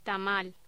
Locución: Tamal